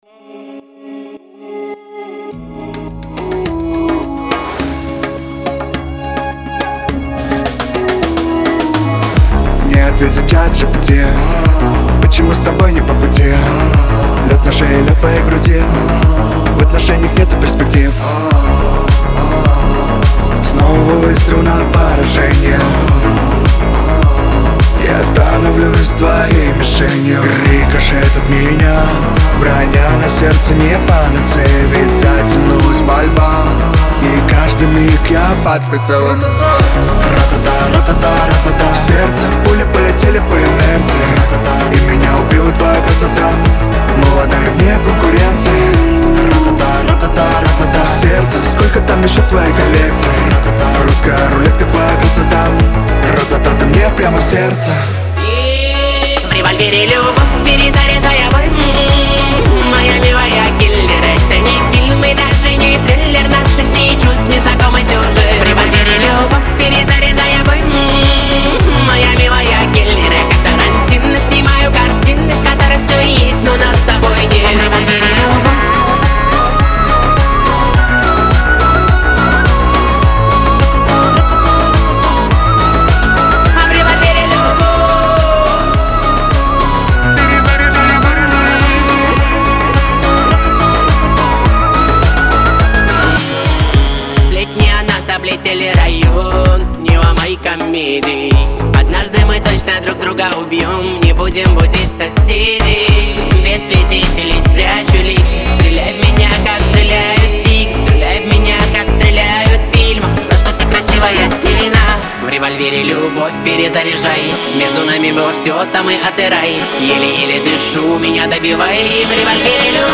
pop
дуэт , эстрада